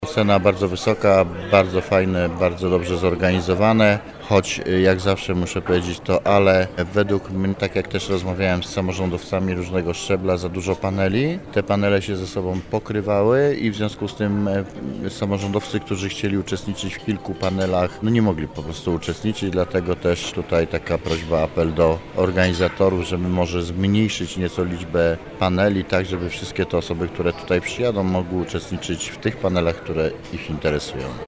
Starosta Roman Potocki wystawił także ocenę tegorocznemu forum.